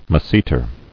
[mas·se·ter]